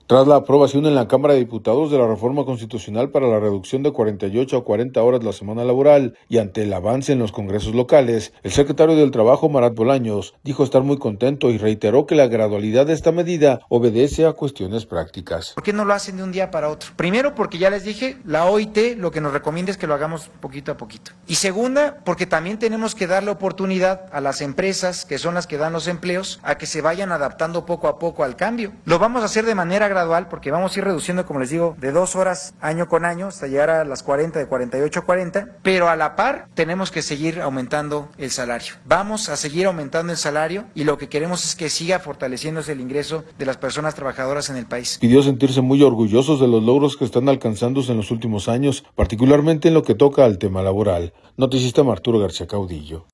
Tras la aprobación en la Cámara de Diputados de la reforma constitucional para la reducción de 48 a 40 horas la semana laboral, y ante el avance en los congresos locales, el secretario del Trabajo, Marath Bolaños, dijo estar muy contento, y reiteró que la gradualidad de esta medida obedece a cuestiones prácticas.